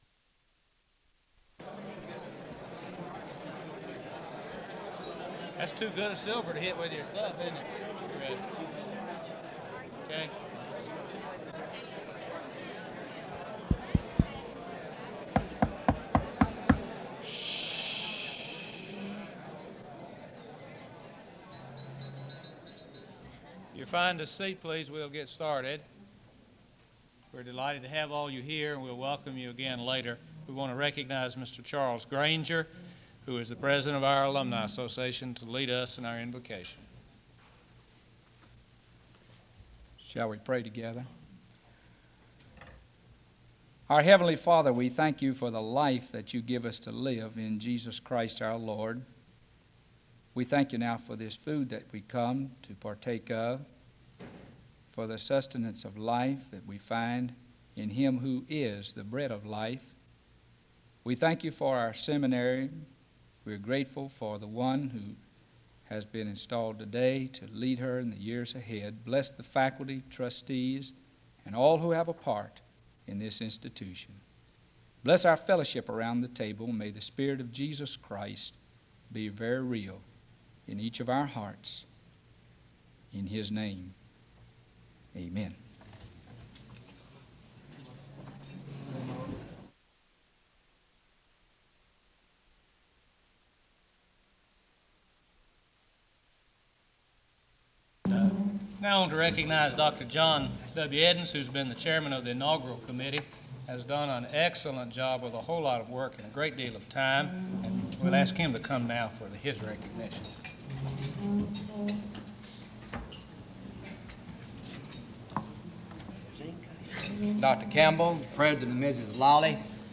This luncheon was a smaller gathering of friends and faculty following the ceremony
Invocation
Benediction